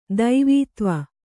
♪ daivītva